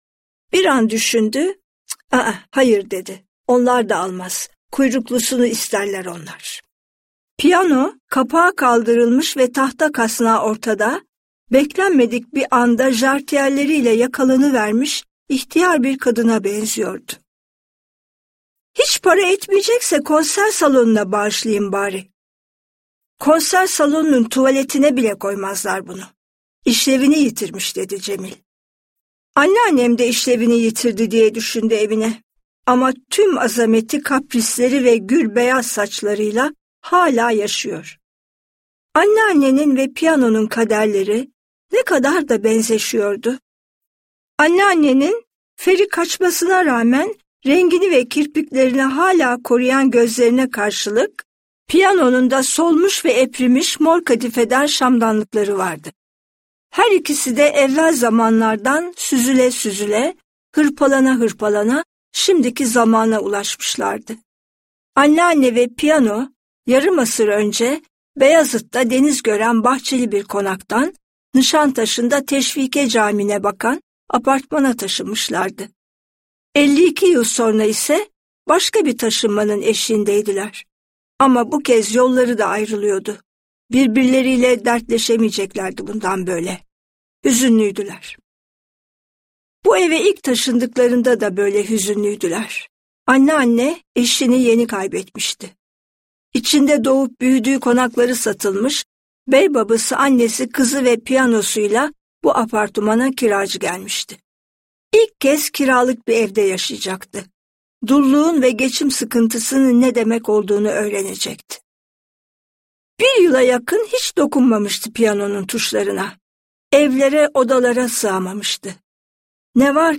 Seslendiren
AYŞE KULİN